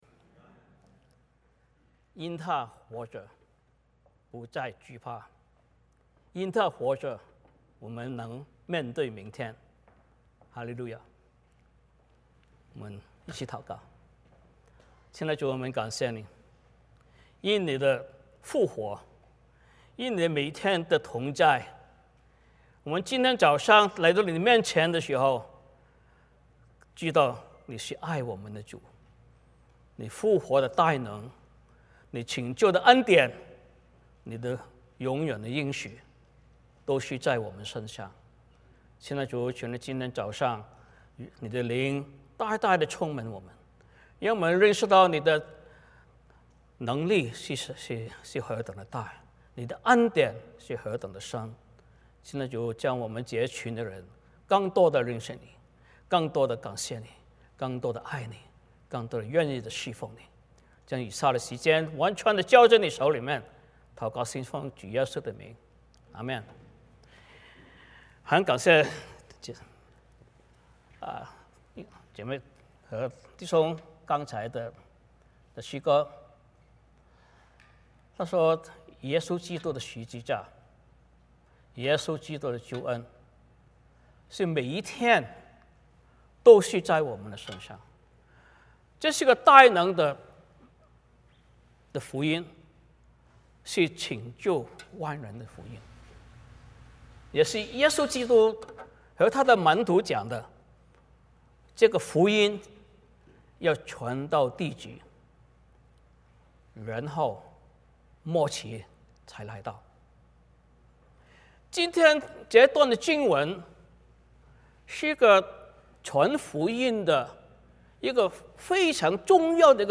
使徒行传 17:10-34 Service Type: 主日崇拜 欢迎大家加入我们的敬拜。